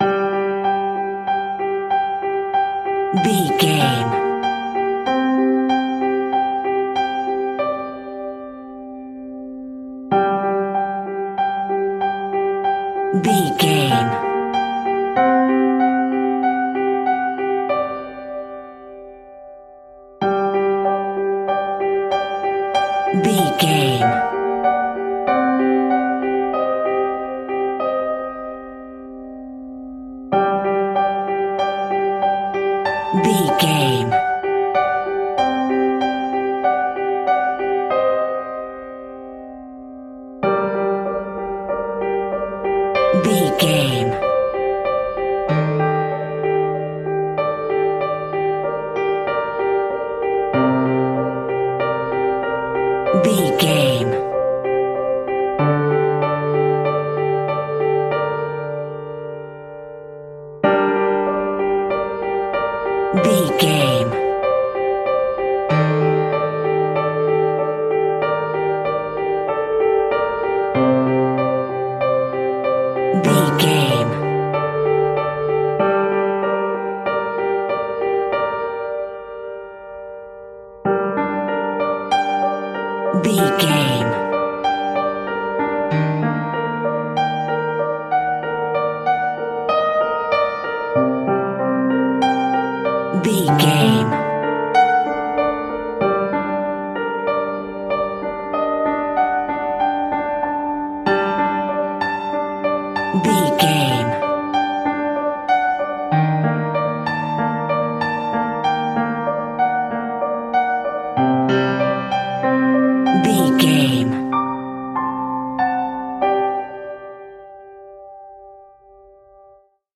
Slow and Scary Piano Music Cue.
Aeolian/Minor
A♭
tension
ominous
eerie